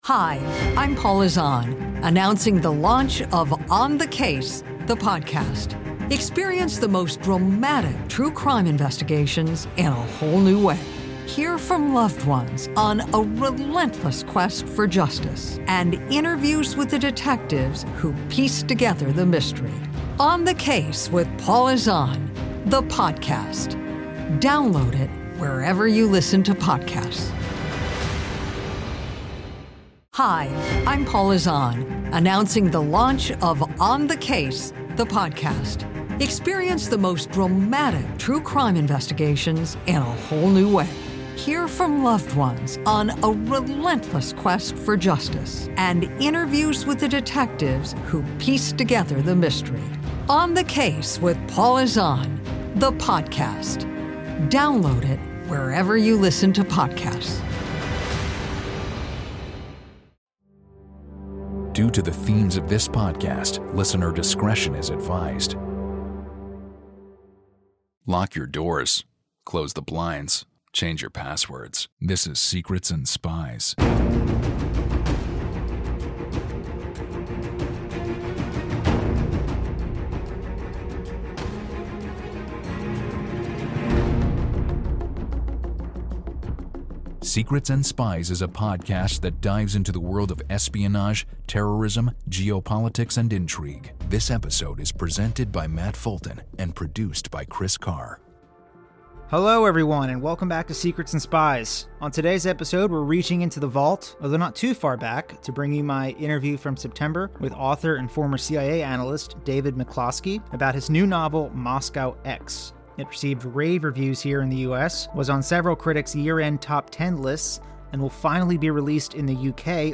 For any fans of the spy genre, this is not an interview you want to miss.